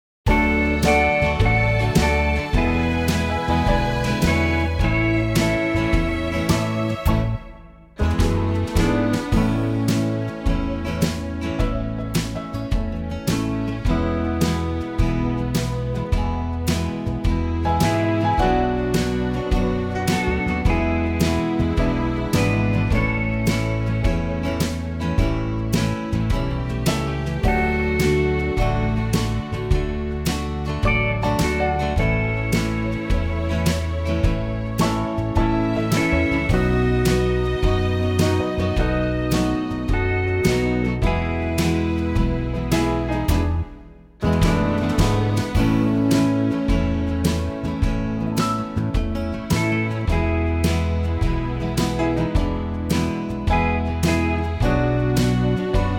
Unique Backing Tracks
4 bar intro and vocal in at 10 seconds
key - A - vocal range - G# to C#
Superb country arrangement